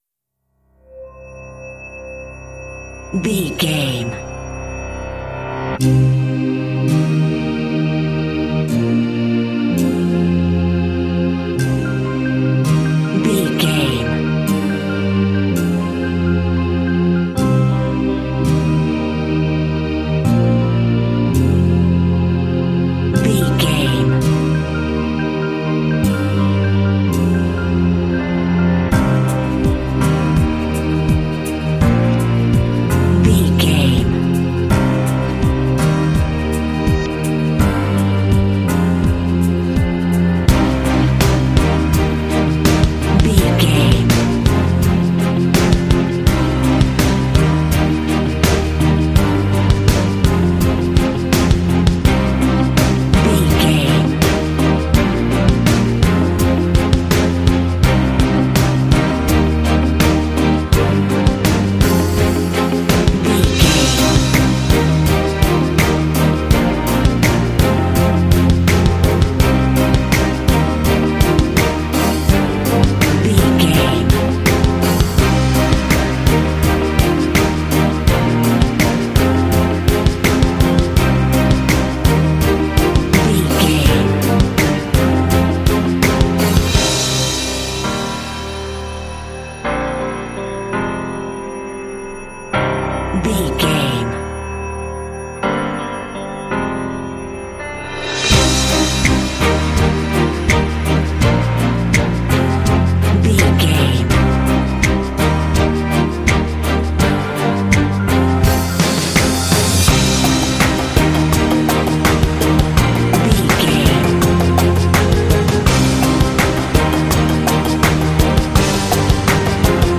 Epic / Action
Aeolian/Minor
powerful
epic
inspirational
synthesiser
piano
drums
strings
symphonic rock
cinematic
classical crossover